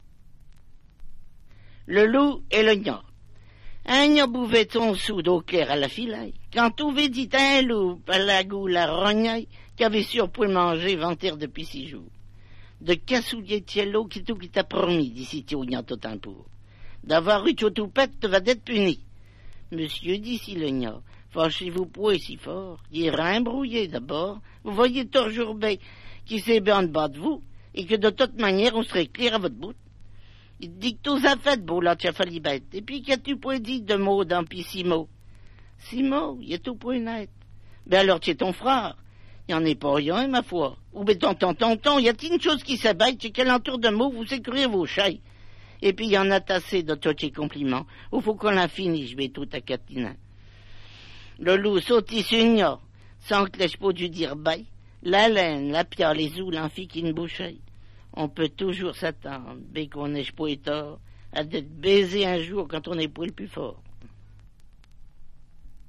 Version maraîchine et traduction